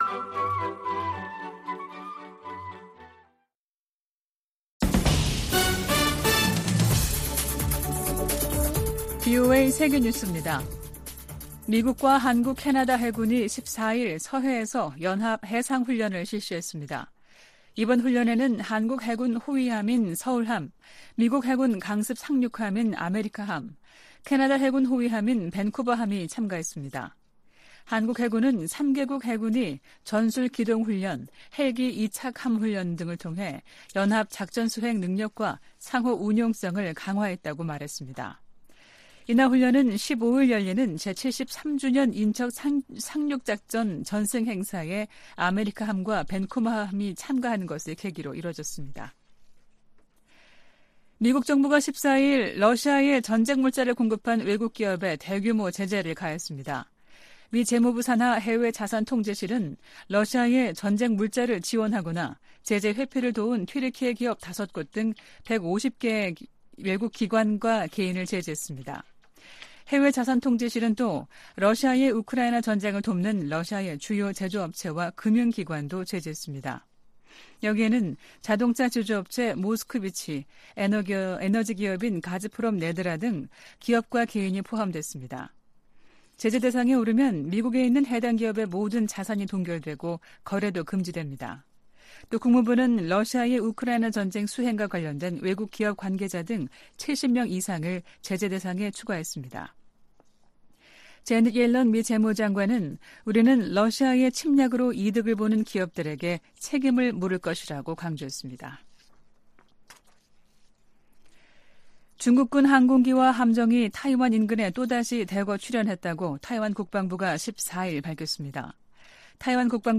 VOA 한국어 아침 뉴스 프로그램 '워싱턴 뉴스 광장' 2023년 9월 15일 방송입니다. 러시아를 방문 중인 김정은 국무위원장이 푸틴 대통령의 방북을 초청한 것으로 북한 관영 매체가 보도했습니다.